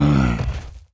spawners_mobs_mummy_death.1.ogg